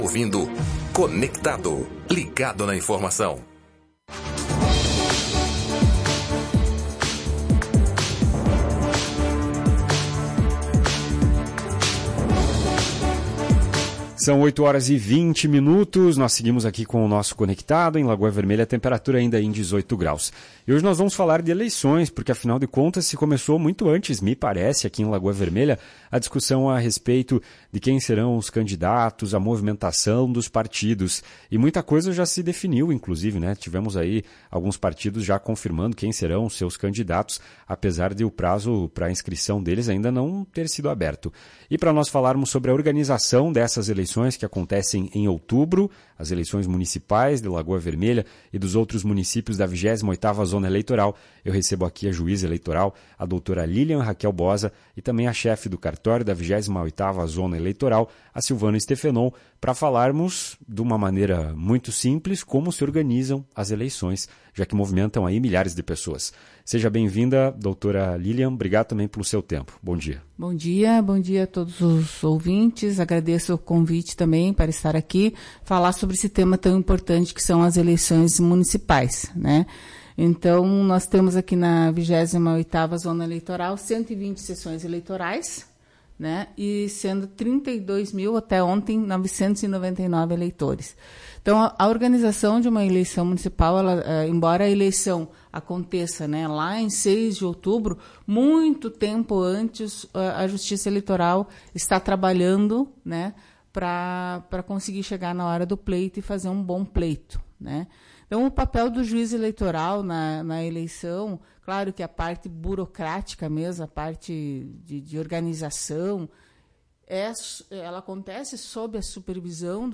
Em uma entrevista nesta quinta-feira